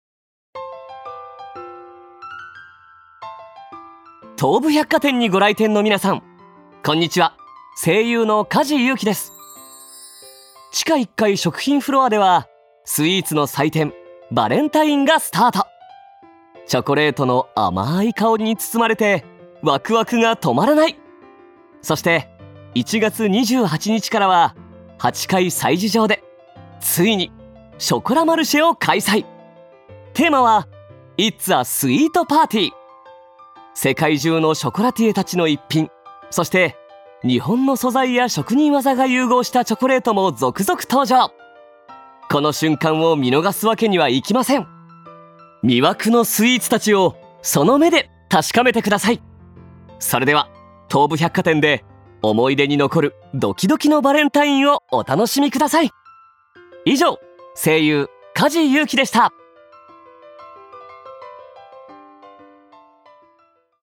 声優 梶 裕貴さん
その声に、人間の脳と心に癒しの効果を与えるという「1/fゆらぎ」の響きを持つ。
1館内放送
■館内放送 Part1(1/15(木)～27(火)に館内で流していた音声) をホームページで特別公開中！